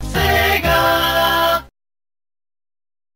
changed alarm sound